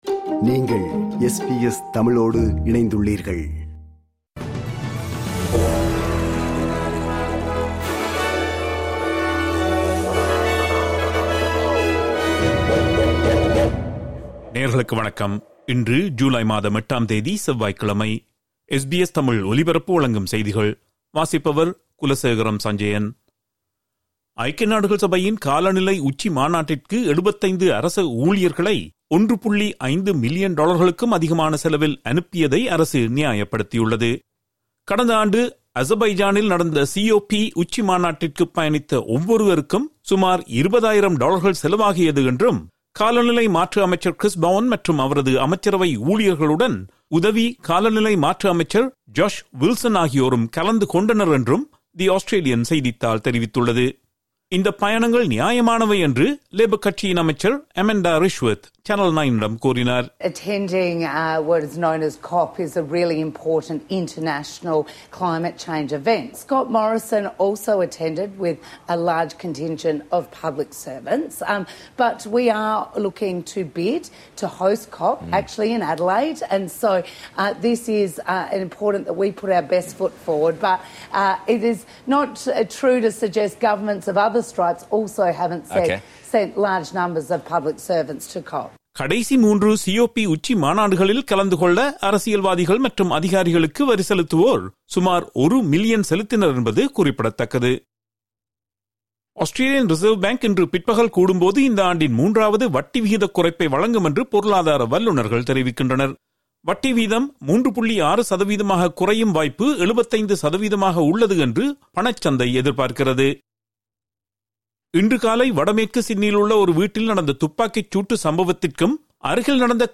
SBS தமிழ் ஒலிபரப்பின் இன்றைய (செவ்வாய்க்கிழமை 08/07/2025) செய்திகள்.